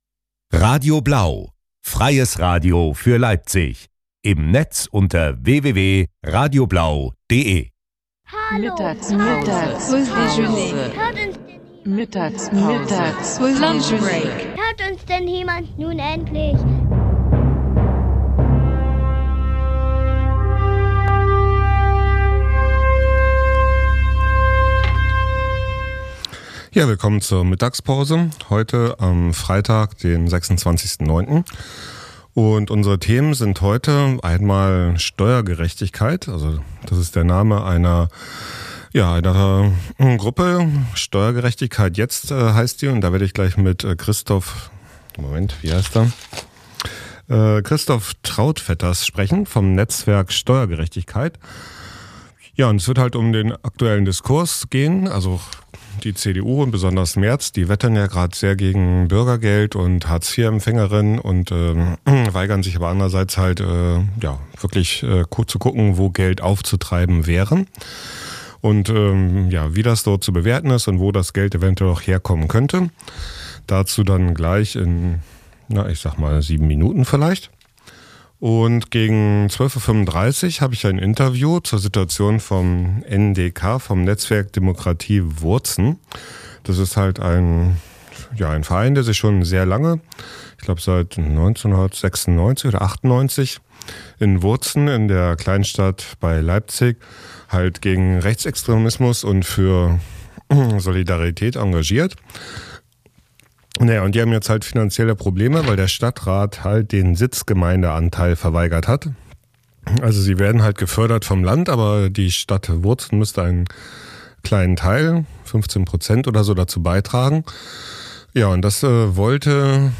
Mittags in Leipzig mit Gesprächen zum Tag.